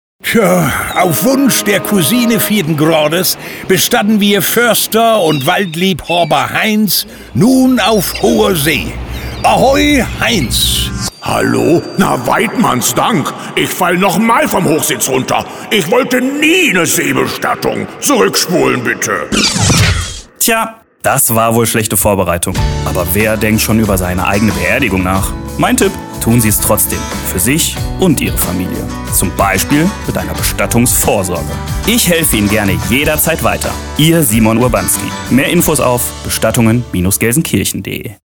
Unsere aktuellen Radiospots im Radio Emscher Lippe.